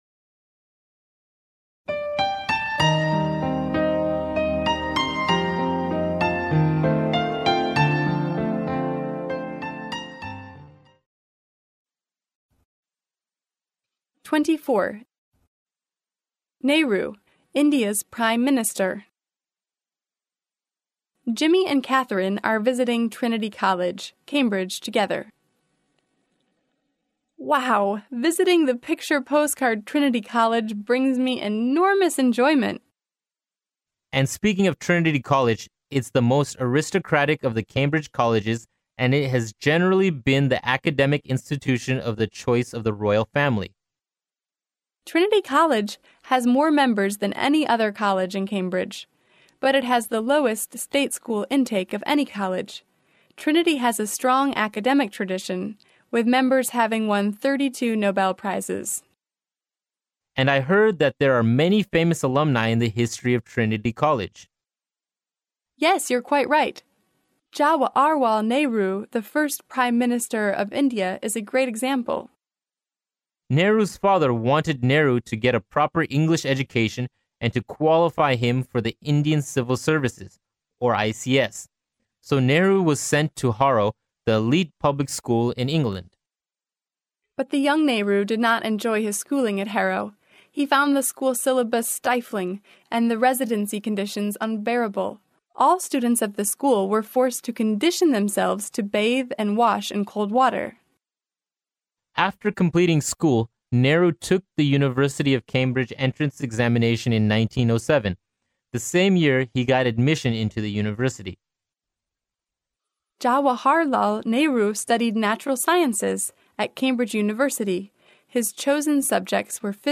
剑桥大学校园英语情景对话24：印度总理尼赫鲁 （mp3+中英）